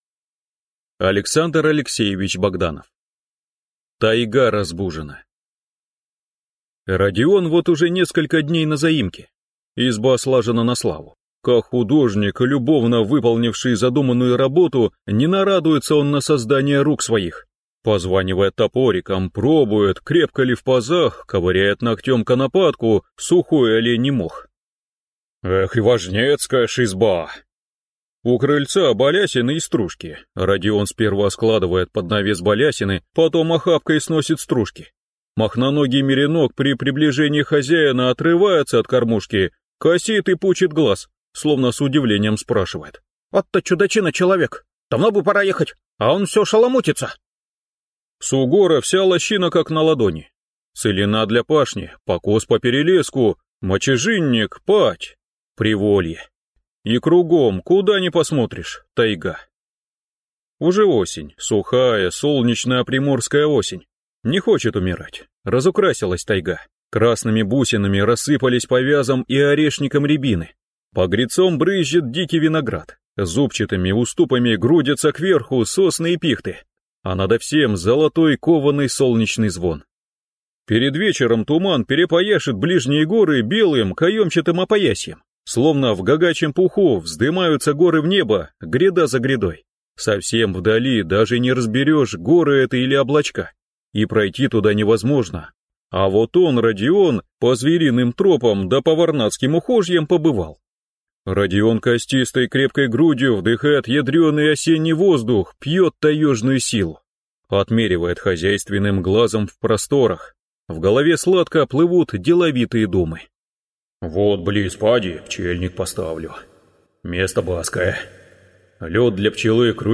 Аудиокнига Тайга разбужена | Библиотека аудиокниг